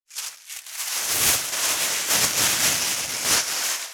601ゴミ袋,スーパーの袋,袋,買い出しの音,ゴミ出しの音,袋を運ぶ音,
効果音